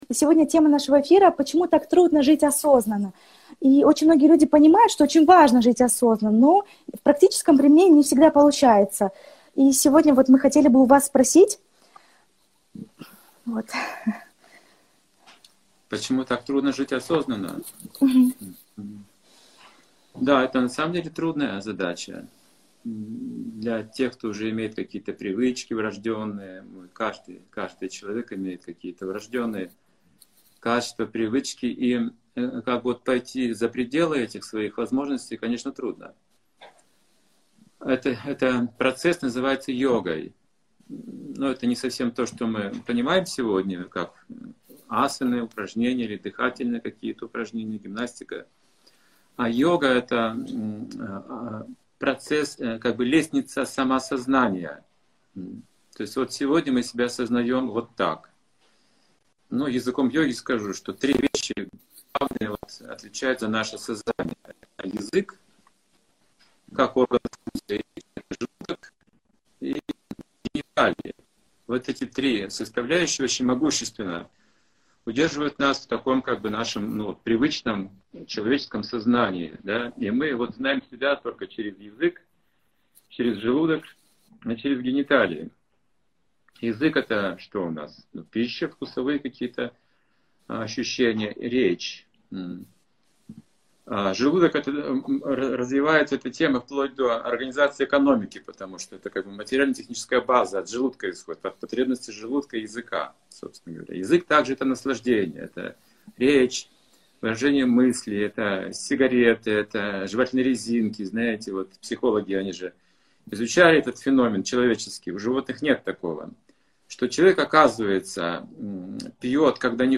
Беседа